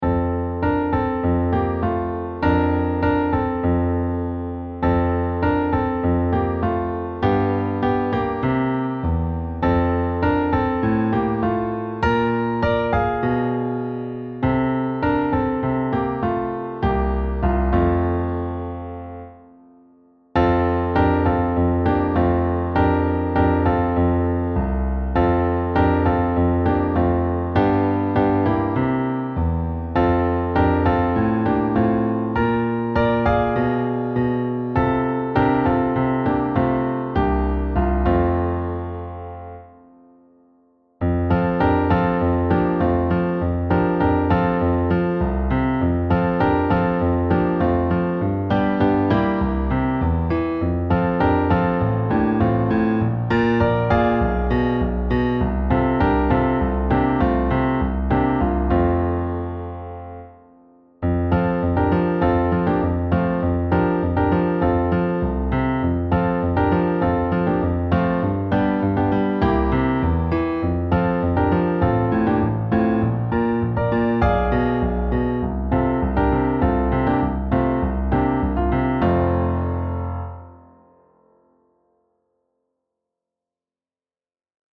Instrumentierung: Klavier solo